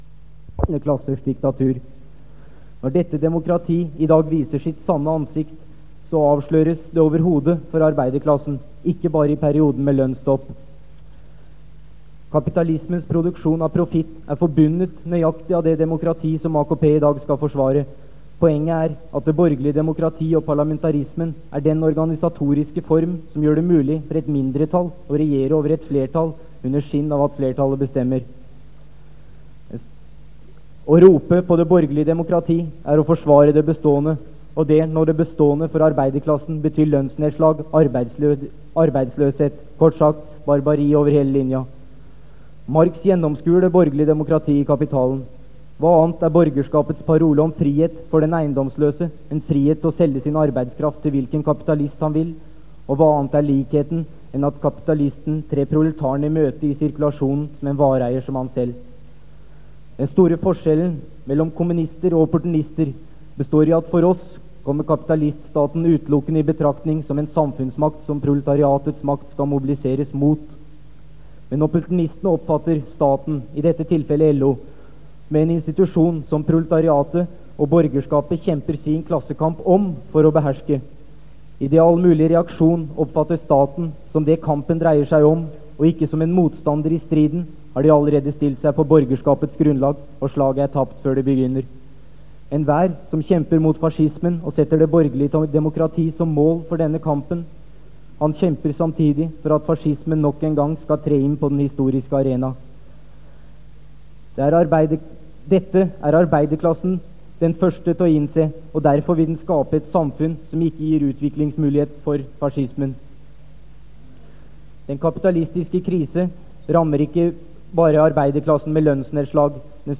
Det Norske Studentersamfund, Generalforsamling, 27.11.1976 (fil 1-3:5)